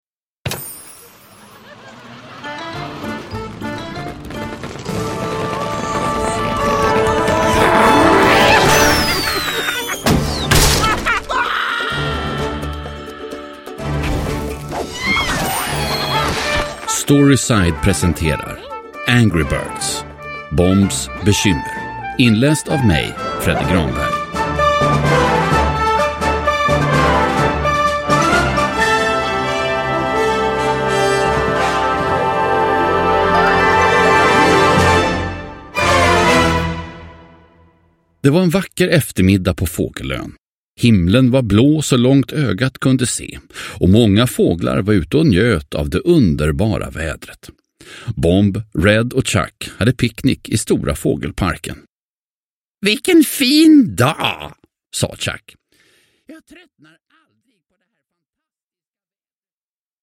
Angry Birds - Bombs bekymmer – Ljudbok – Laddas ner